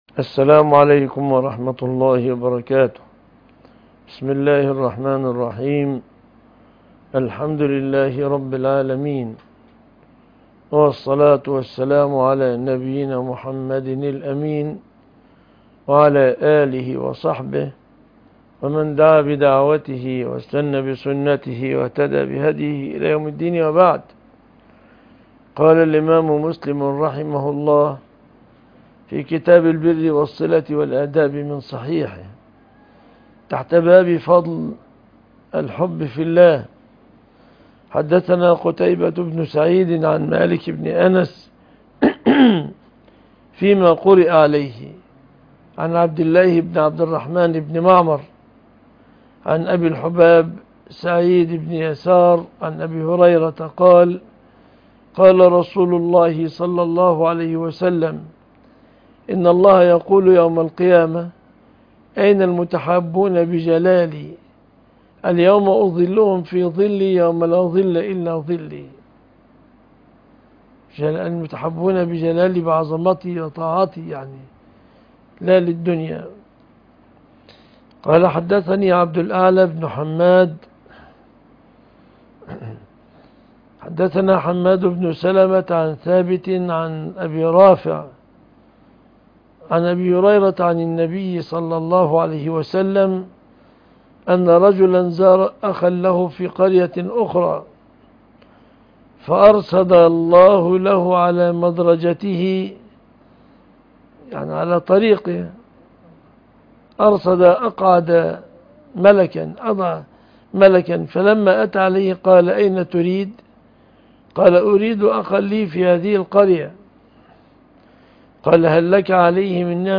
الحديث وعلومه     شرح الأحاديث وبيان فقهها